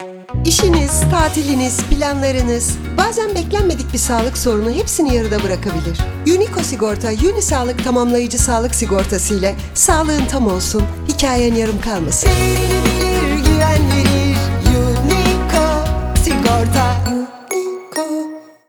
Radyo Reklamları